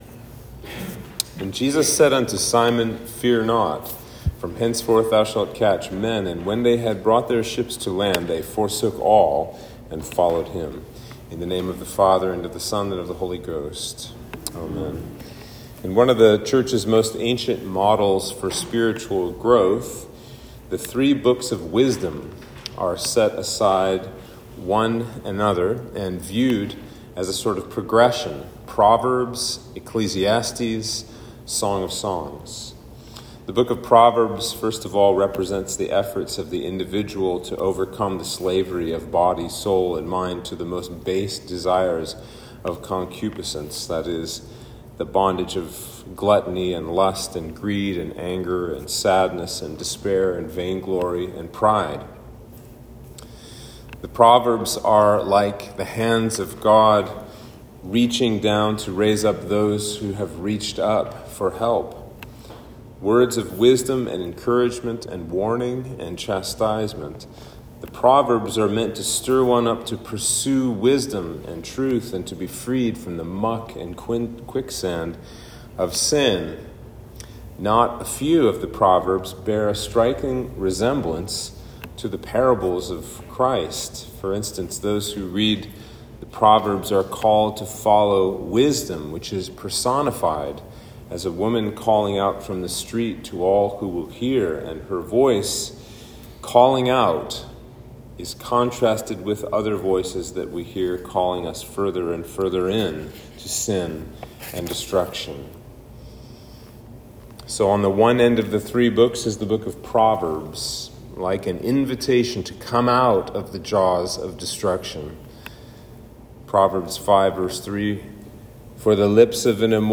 Sermon for Trinity 5